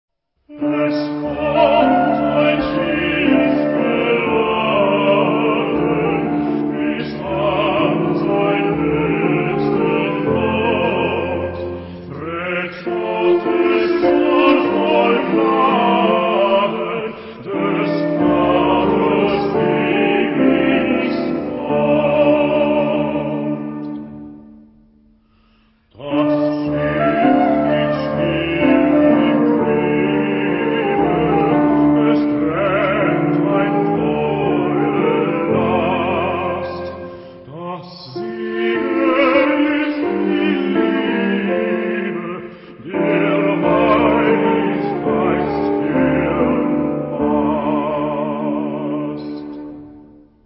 Genre-Style-Form: Sacred ; Lied
Type of Choir: SA  (2 women voices )
Instruments: Melody instrument (1) ; Keyboard (1)
Tonality: D minor